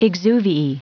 Prononciation du mot exuviae en anglais (fichier audio)
Prononciation du mot : exuviae